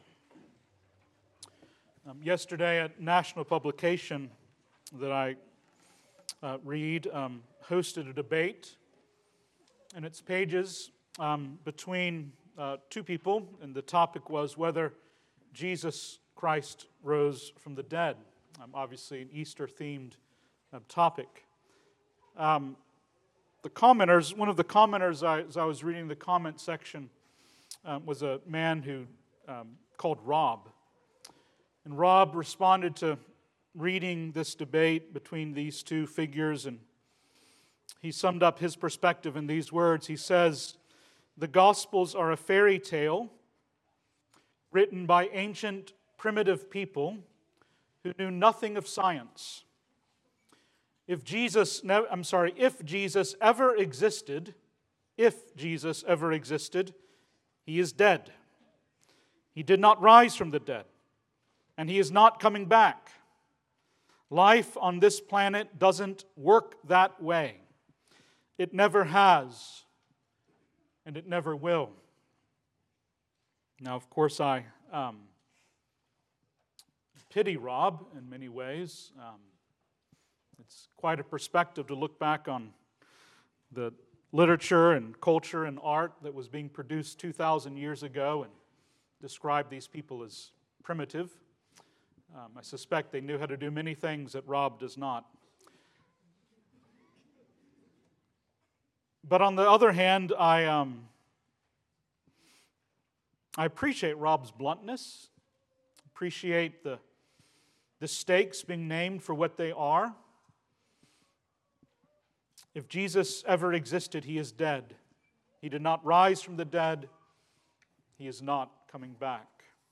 Services of Colleyville Presbyterian Church
Weekly audio from our 9:30 Sunday School and 11am worship service.